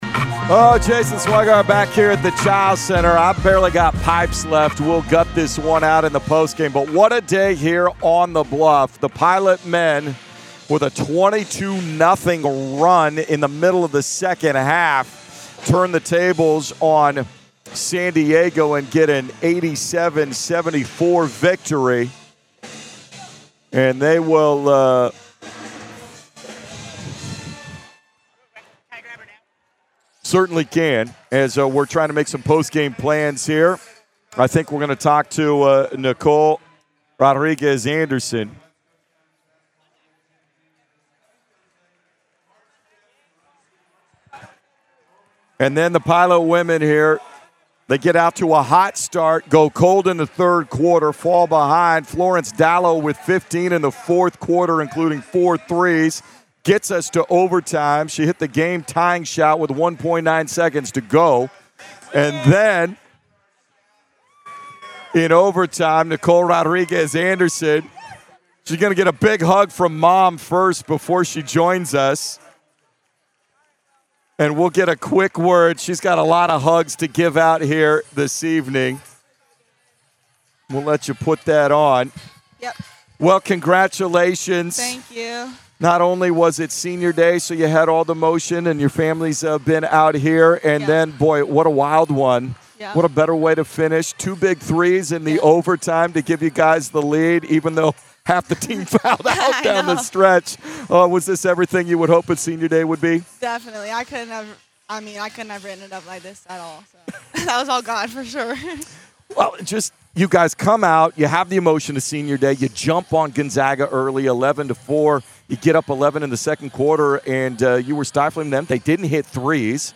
Postgame Radio 2-28